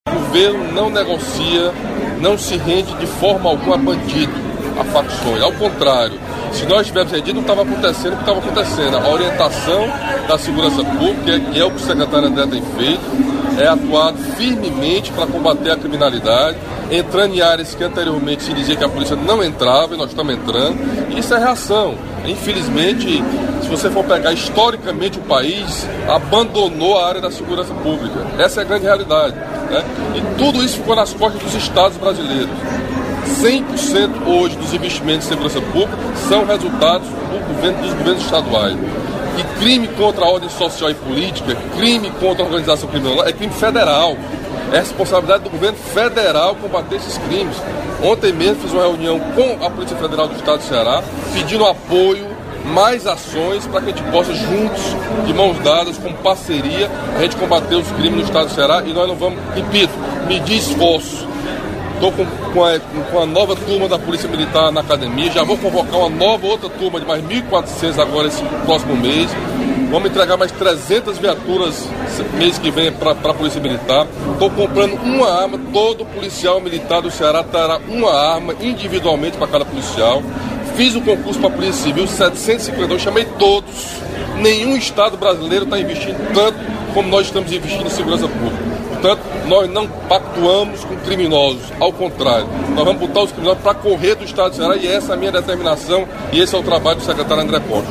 Durante seu pronunciamento no evento do Garantia Safra 2016/2017, o governador Camilo Santana falou sobre a segurança pública no Estado do Ceará.